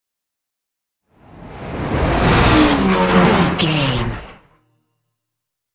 Sci fi vehicle whoosh large
Sound Effects
dark
futuristic
whoosh